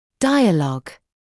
[‘daɪəlɔg][‘дайэлог]диалог; беседа